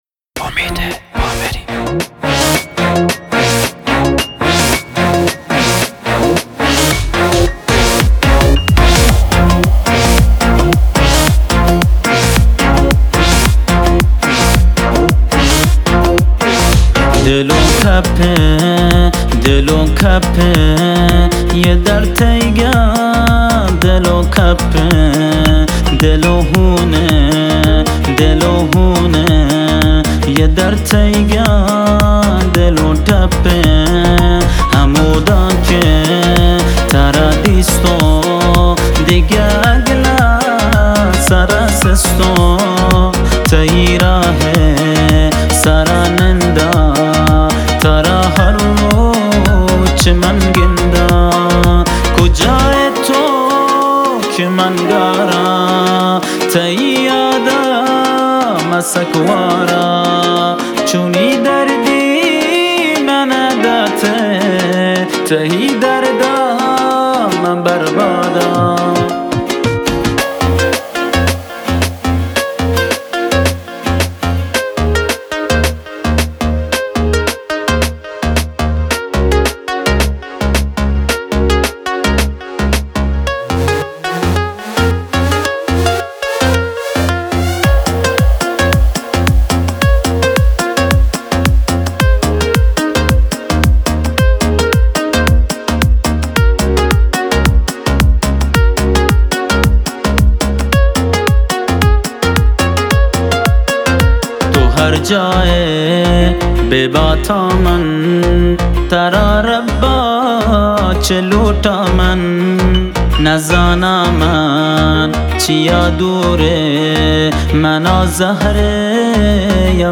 آهنگ بلوچی